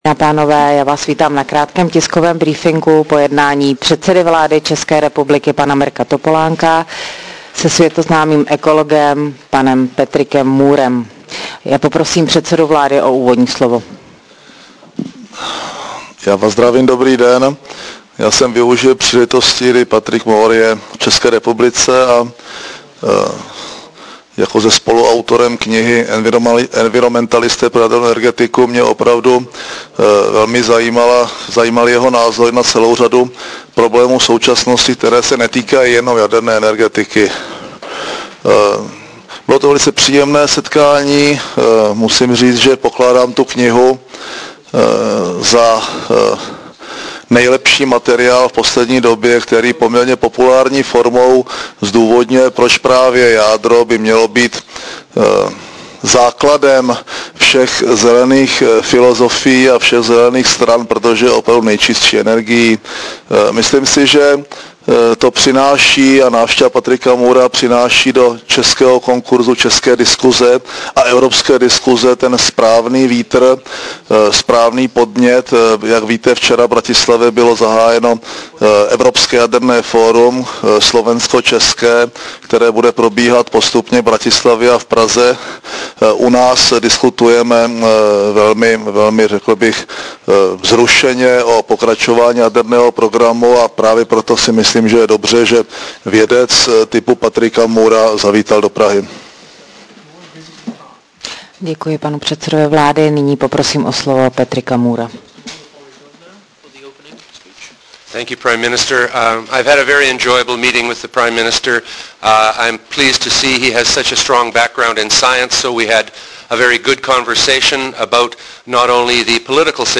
Tiskový briefing předsedy vlády Mirka Topolánka po jednání s Patrickem Moorem v Kramářově vile 27.11.2007